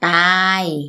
– dtaay